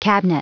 Prononciation du mot cabinet en anglais (fichier audio)
Prononciation du mot : cabinet